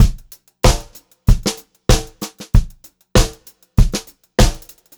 96CL3BEAT3-R.wav